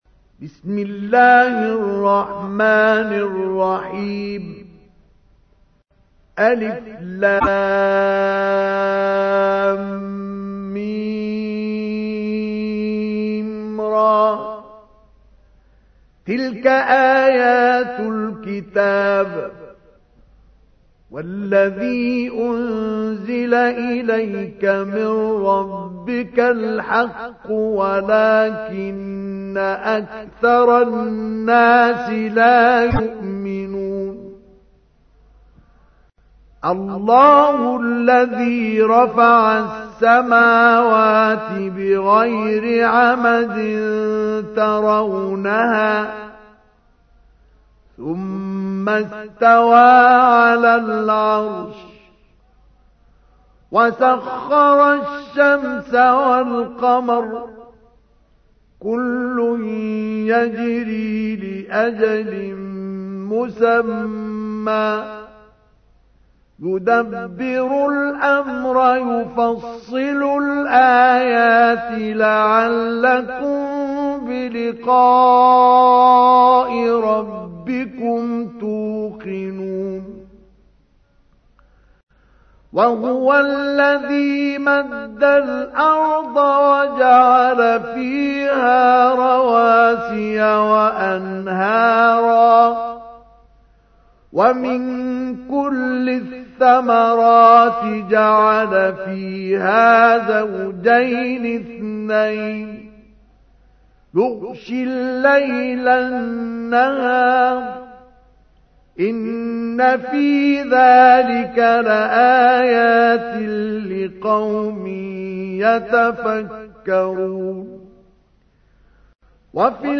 تحميل : 13. سورة الرعد / القارئ مصطفى اسماعيل / القرآن الكريم / موقع يا حسين